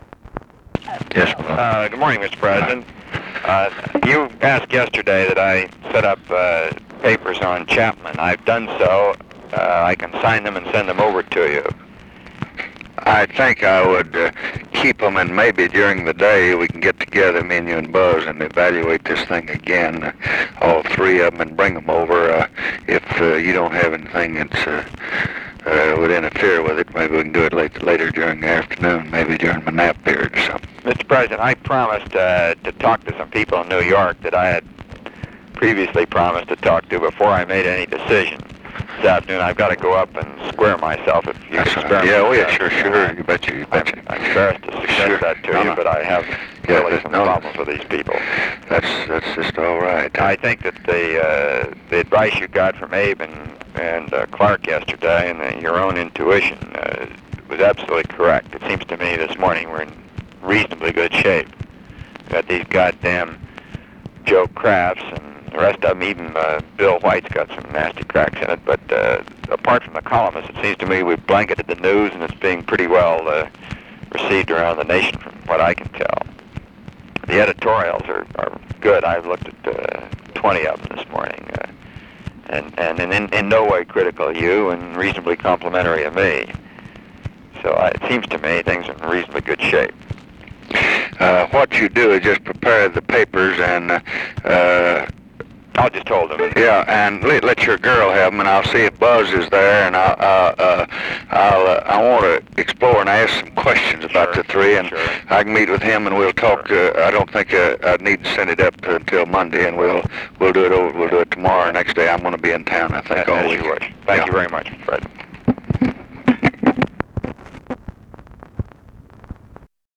Conversation with ROBERT MCNAMARA, November 30, 1967
Secret White House Tapes